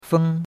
feng1.mp3